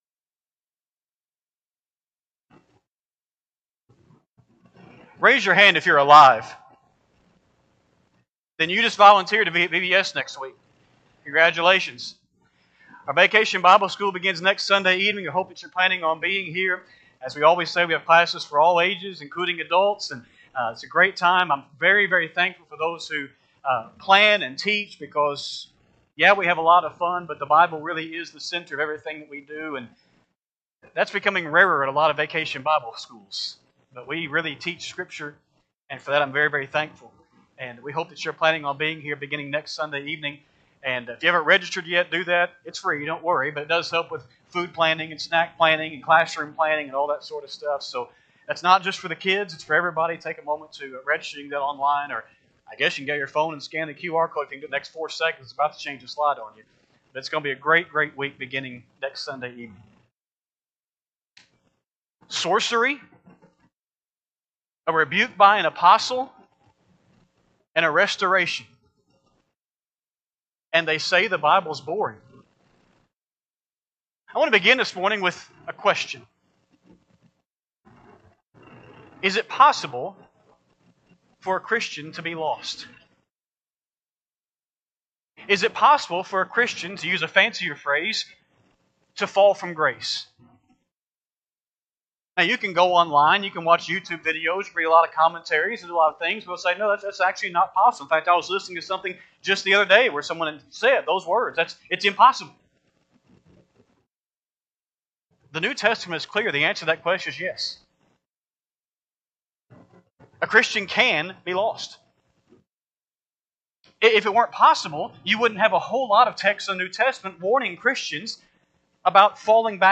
Sunday AM Sermon
5-25-25-Sunday-AM-Sermon.mp3